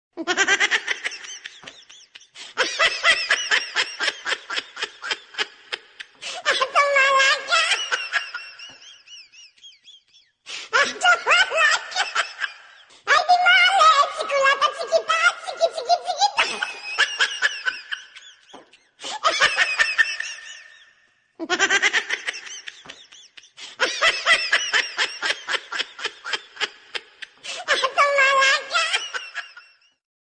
Hiệu ứng âm thanh mp3 chất lượng cao mô tả tiếng cười của nhiều người, chỉ hoạt động vui vẻ của con người, tiếng cười giòn tan của khán giả sau khi xem được cảnh phim hài...